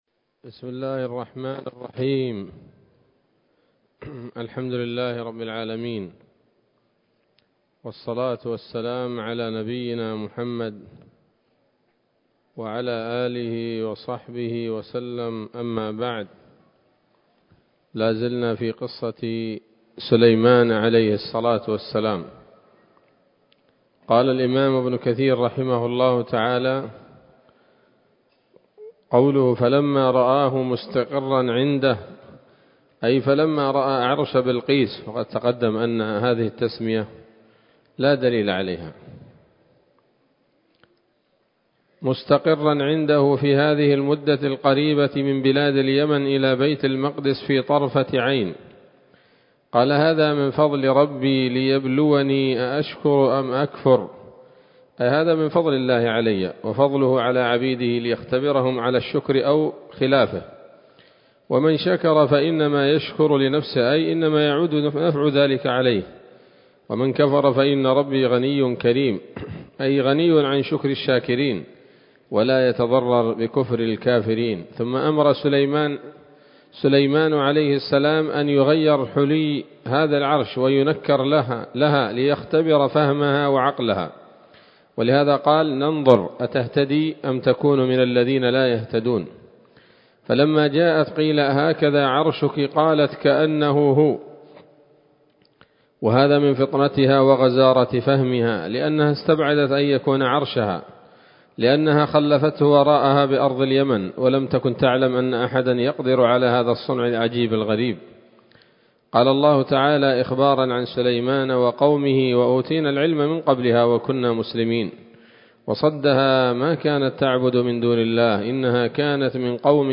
‌‌الدرس الثالث والعشرون بعد المائة من قصص الأنبياء لابن كثير رحمه الله تعالى